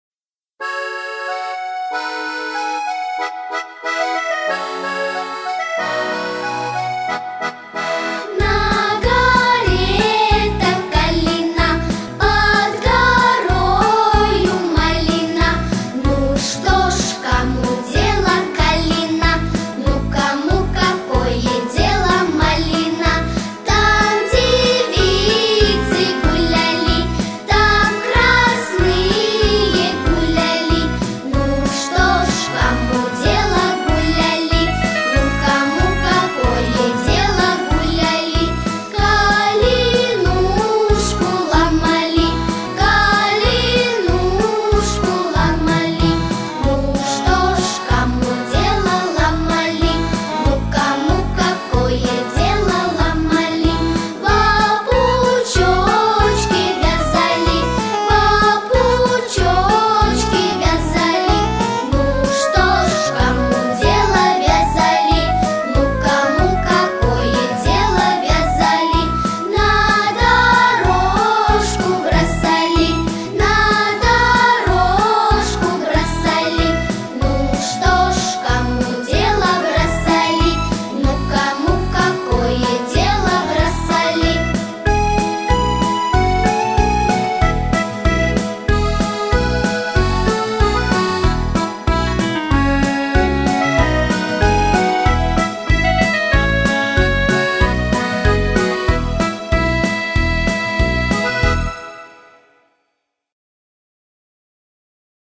Русская народная песня в обр.
плюс, детский хор
русской народной песне